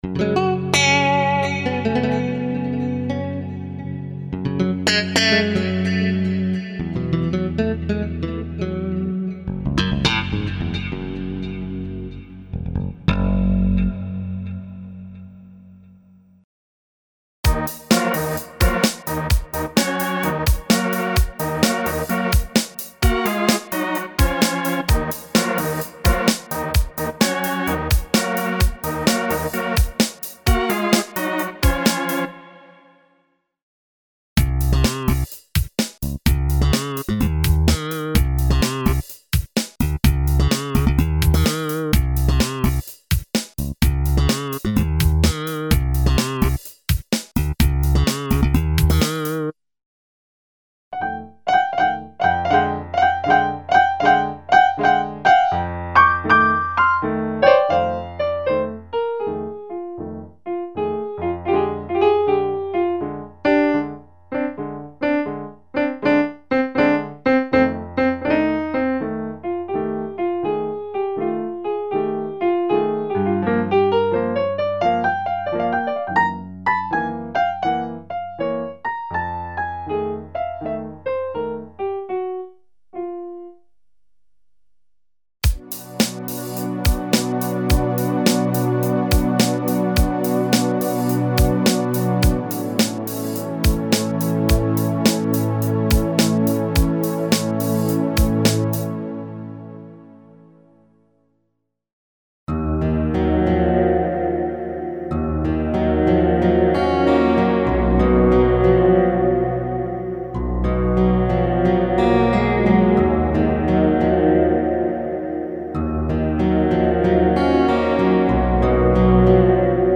Rock, Jazz and Ethno sound banks (el. clean & dist. guitars, basses, organs, pianos and ethno layers).
Info: All original K:Works sound programs use internal Kurzweil K2500 ROM samples exclusively, there are no external samples used.